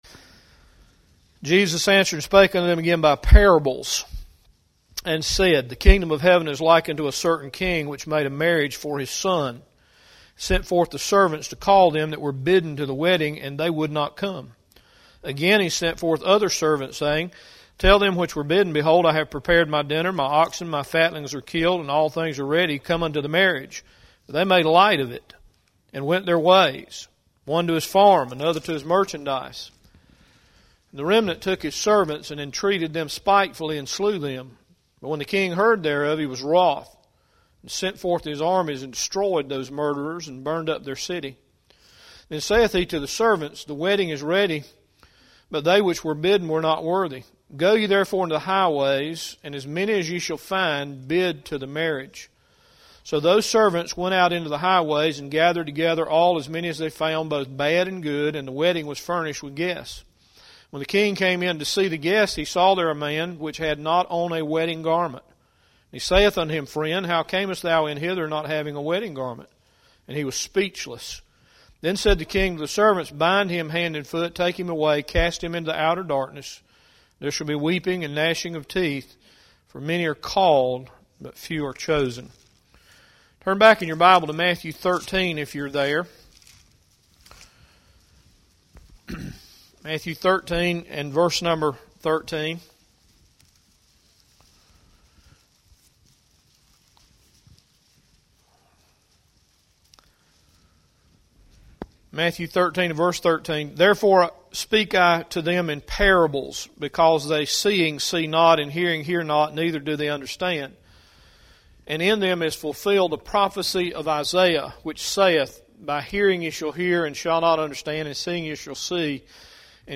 May 6, 2012 AM Service Matthew Series #58 – Bible Baptist Church